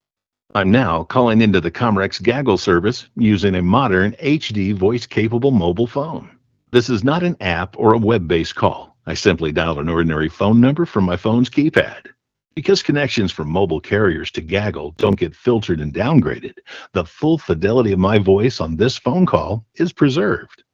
Mobile phone calls made to or from your designated Hotline number will sound dramatically improved with higher fidelity than a normal phone call.
Hotline-mobile-phone-recording.wav